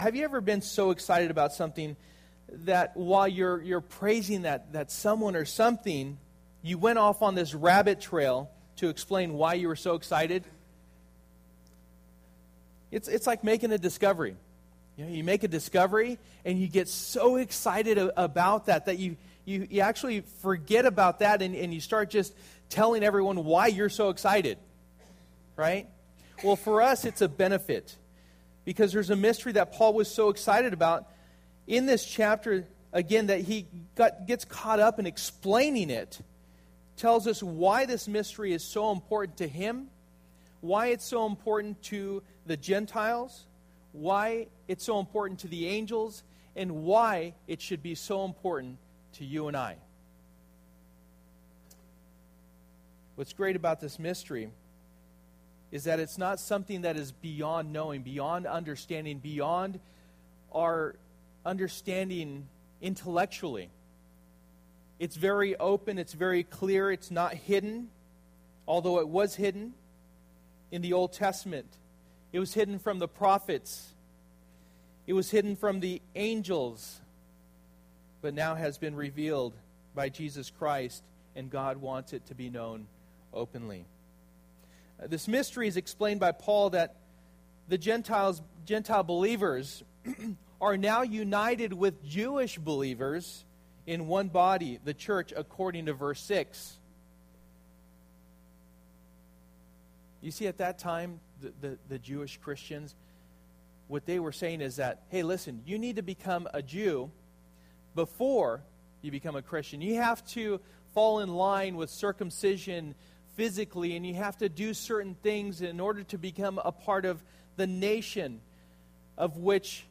A United Church Passage: Ephesians 3:1-21 Service: Sunday Morning %todo_render% « Set Apart United We Stand